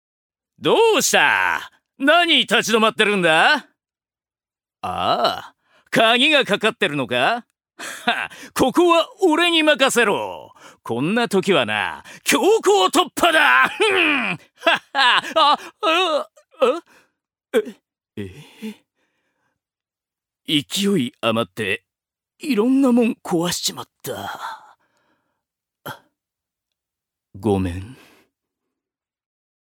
ジュニア：男性
音声サンプル
セリフ３